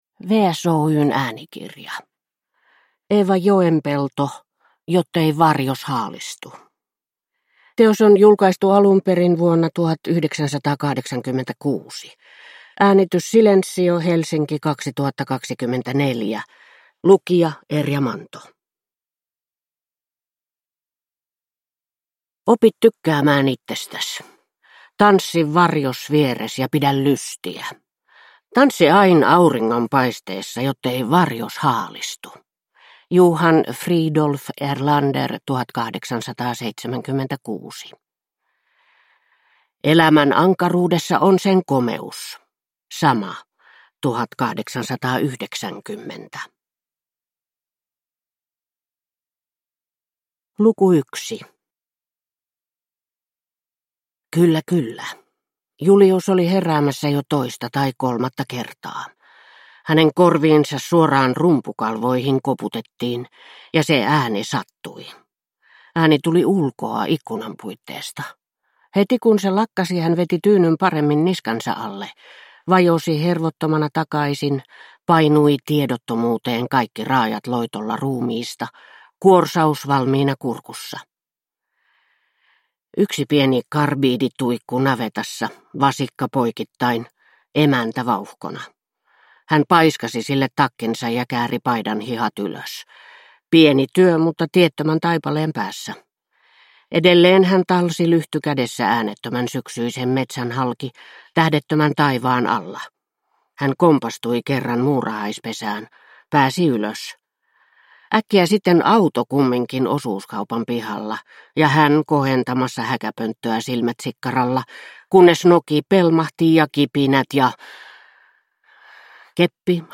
Jottei varjos haalistu (ljudbok) av Eeva Joenpelto